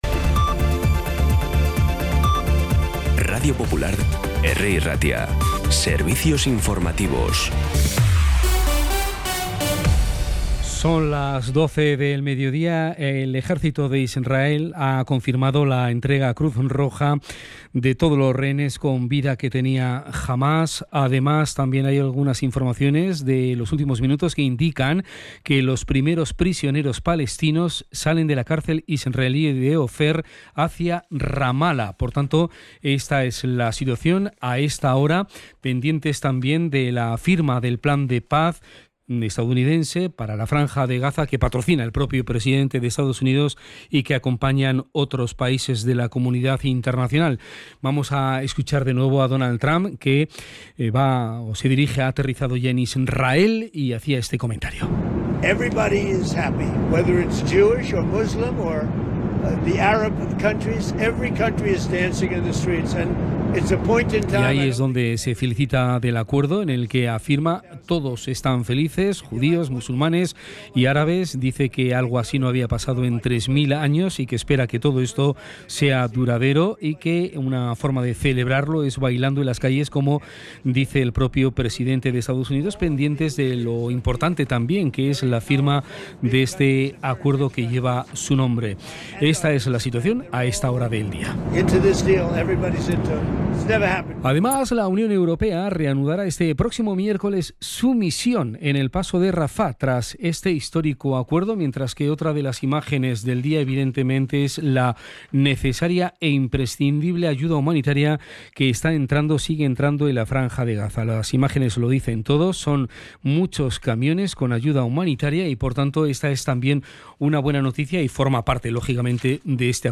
Las noticias de Bilbao y Bizkaia del 13 de octubre a las 12
Los titulares actualizados con las voces del día. Bilbao, Bizkaia, comarcas, política, sociedad, cultura, sucesos, información de servicio público.